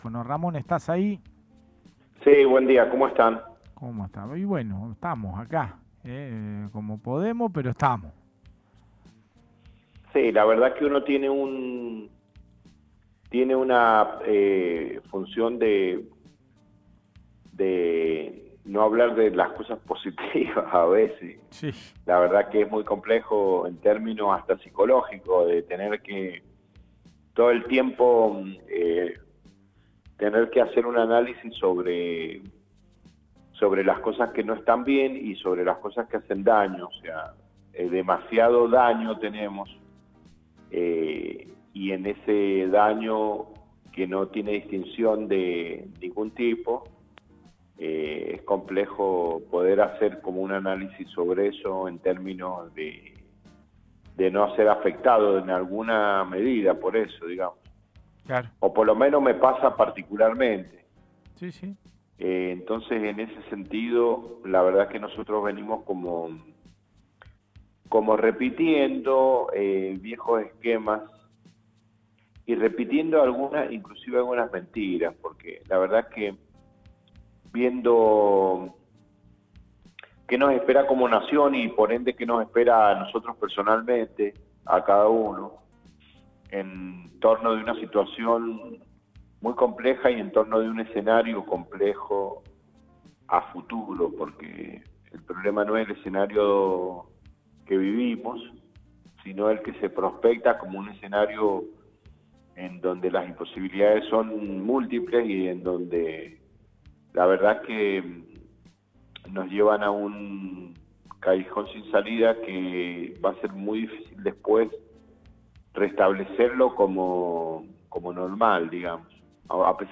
En la columna de medioambiente y energía, nos acompaña como todos los lunes
En dialogo con el programa radial